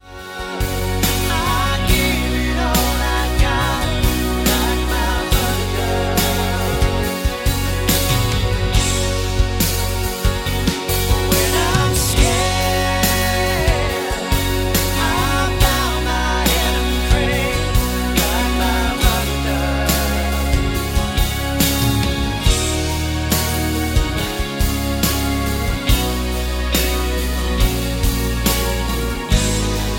F#
Backing track Karaoke
Country, Musical/Film/TV, 2010s